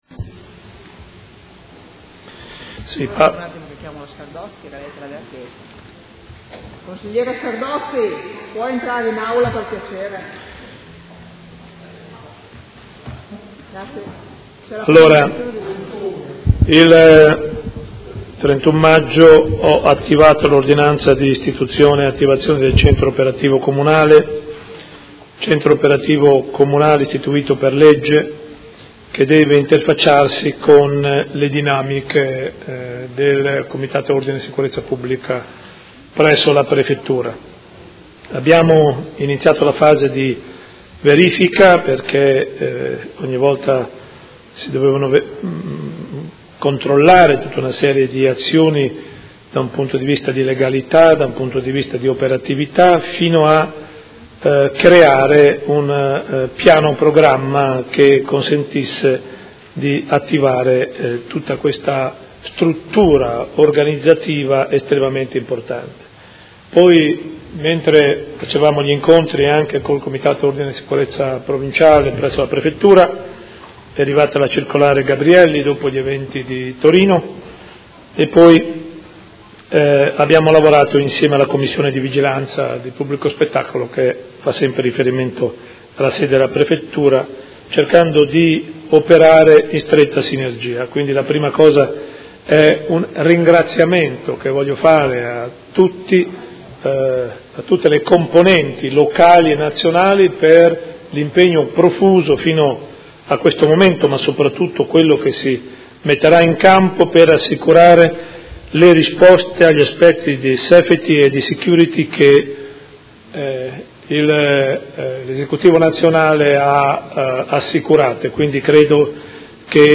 Seduta del 26/06/2017. Comunicazione del Sindaco sul concerto di Vasco Rossi
Audio Consiglio Comunale